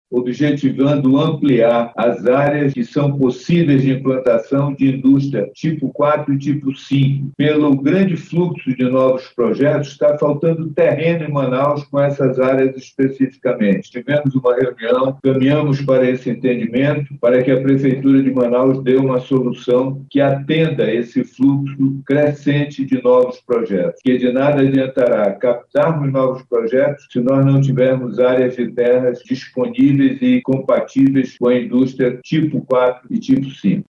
A reunião de número 320 do Conselho de Administração da Suframa – CAS ocorreu na manhã desta quarta-feira 27/08 por videoconferência.
Serafim Corrêa, também, solicitou ao Ministério do Desenvolvimento, Indústria, Comércio e Serviços – MDIC, uma solução para a escassez de terrenos, em Manaus, para a instalação de novas indústrias.
SONORA-3-REUNIAO-CAS-.mp3